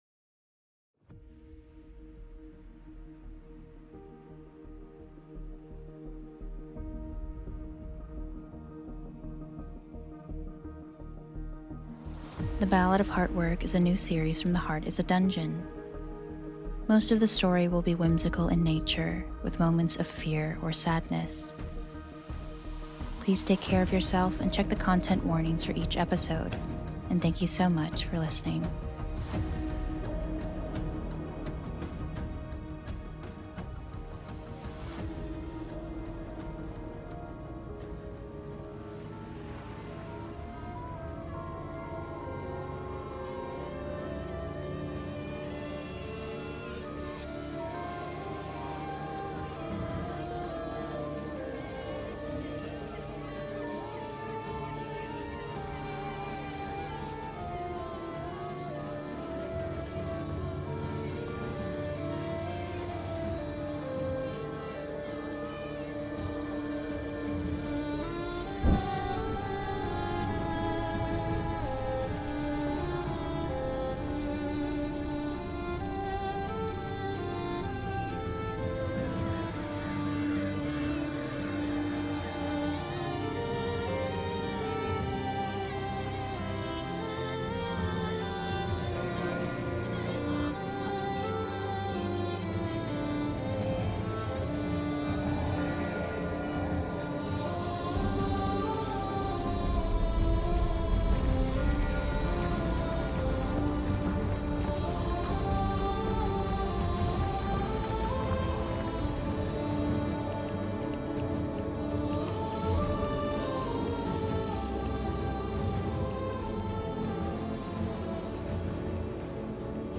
This episode contains some music